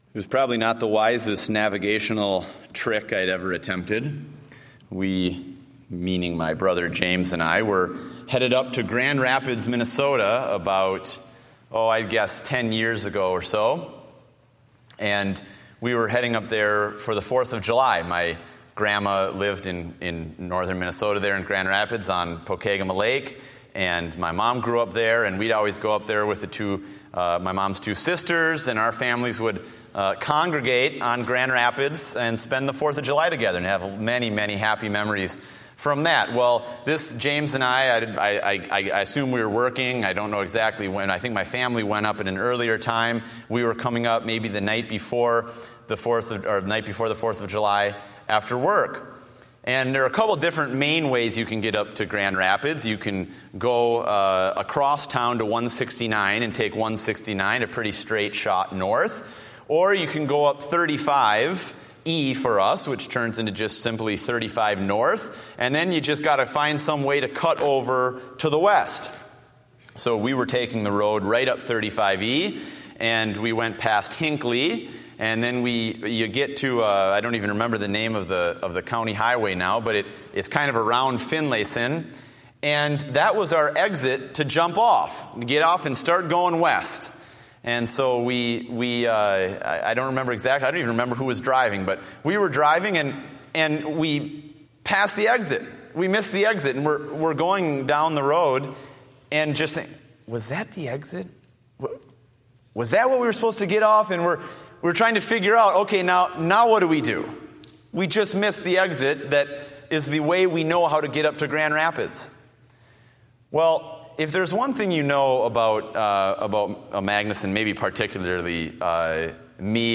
Date: July 20, 2014 (Evening Service)